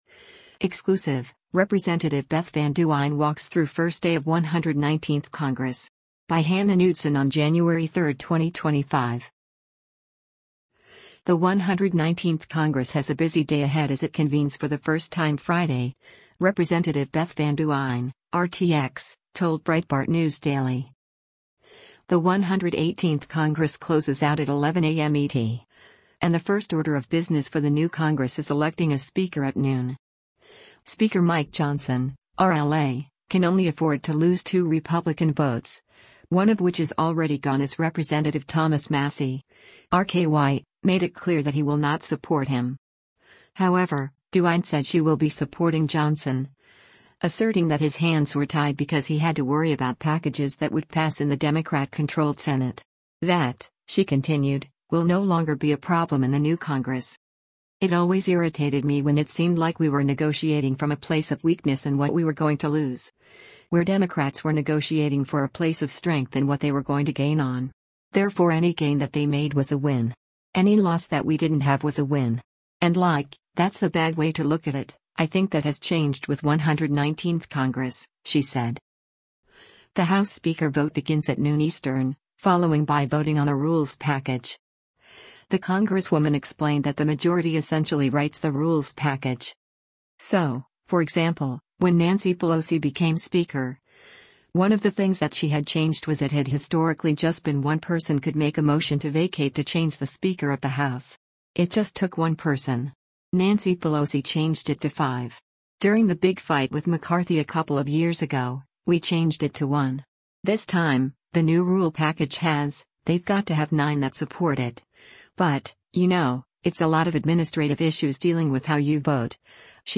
The 119th Congress has a busy day ahead as it convenes for the first time Friday, Rep. Beth Van Duyne (R-TX) told Breitbart News Daily.
Breitbart News Daily airs on SiriusXM Patriot 125 from 6:00 a.m. to 9:00 a.m. Eastern.